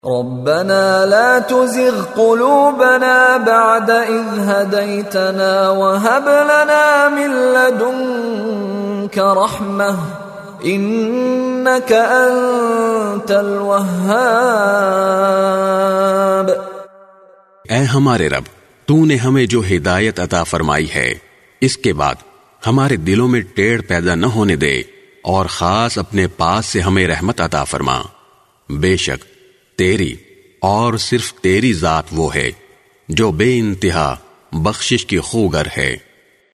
by Mishary Rashid Alafasy
His melodious voice and impeccable tajweed are perfect for any student of Quran looking to learn the correct recitation of the holy book.
rabbana la tuzigh quloobana full dua audio with urdu translation.mp3